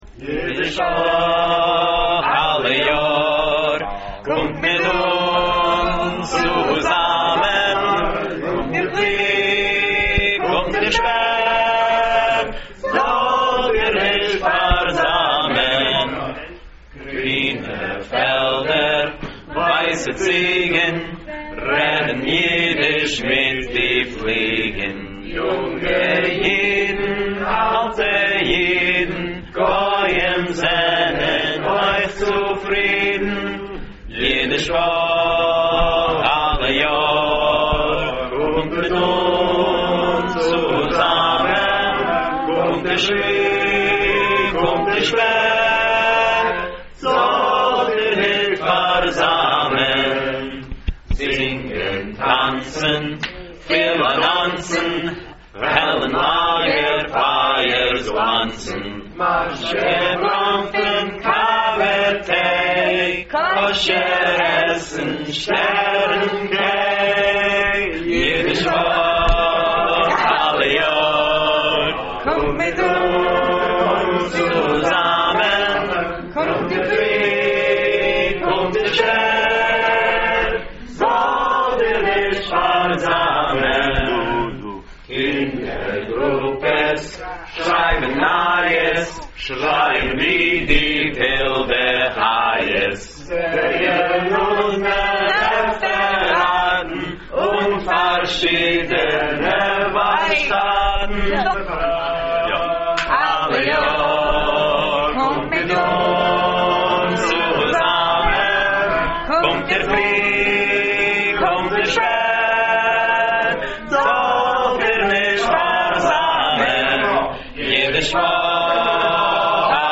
tekst (PDF) muzik (PDF) muzik (abc) rekordirung fun der varshtat